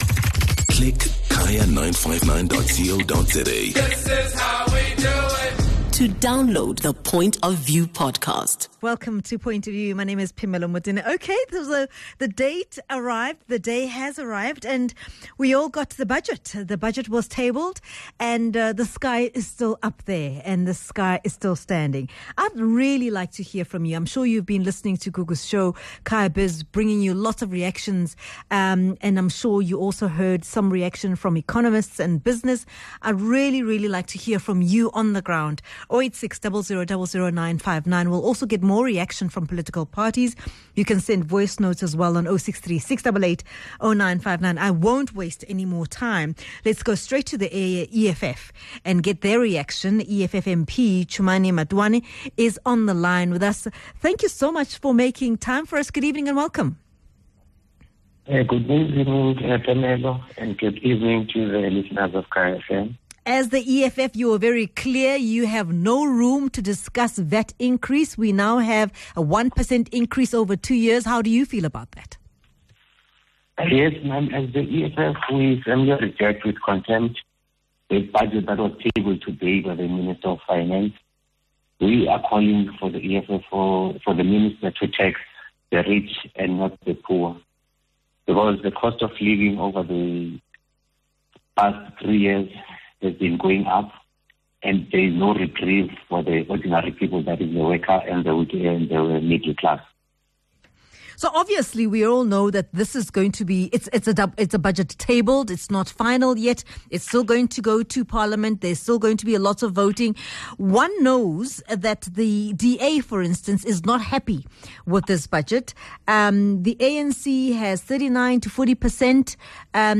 speaks to different parties for reaction EFF MP Chumani Matiwe, DA Spokesperson Willie Aucamp, ActionSA MP Alan Beesley, SAFTU General Secretary Zwelinzima Vavi & MK Party Chief Whip Mzwanele Manyi.